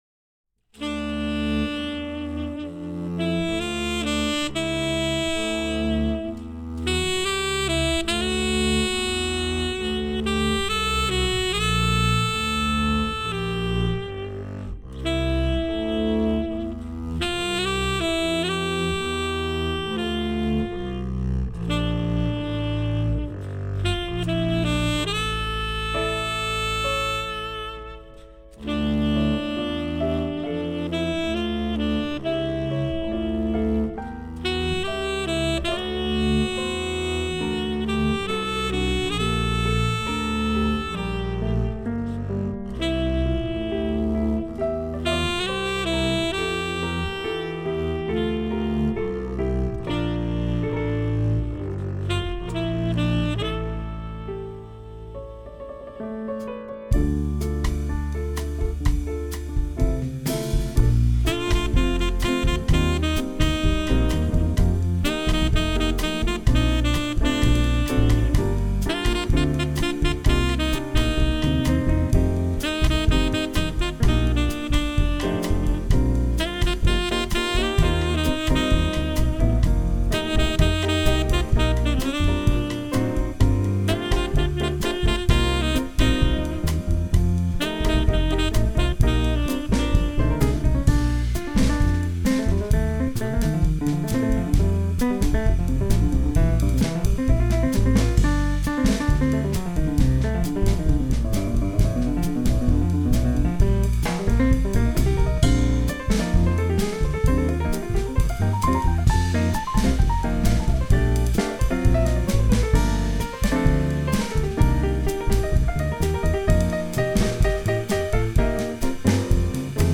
all saxes
piano, electric piano
double bass, banjo
drums